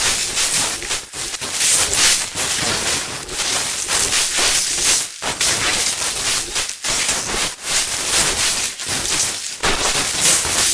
ZombieSkill_SFX
sfx_skill 10_1.wav